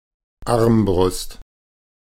Ääntäminen
Synonyymit lock door latch catch Ääntäminen : IPA : /lætʃ/ US : IPA : [lætʃ] Haettu sana löytyi näillä lähdekielillä: englanti Käännös Konteksti Ääninäyte Substantiivit 1.